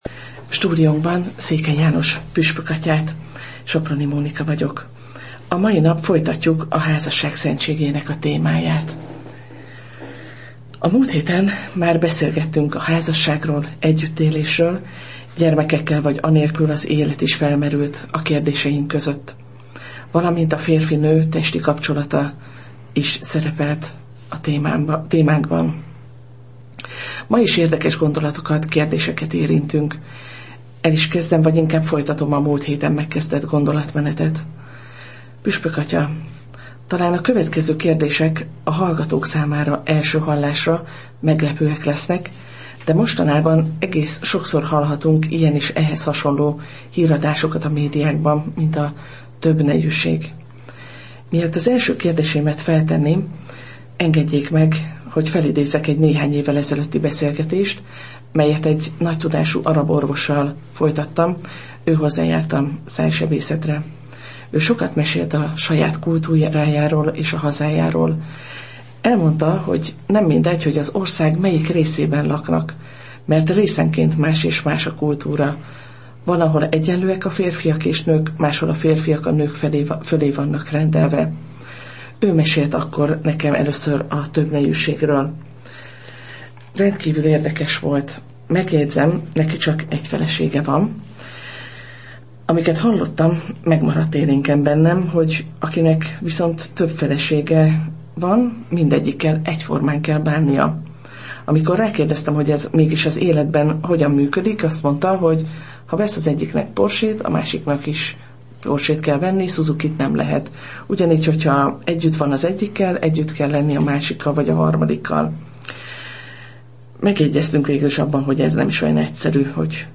Beszélgetés Székely János püspökkel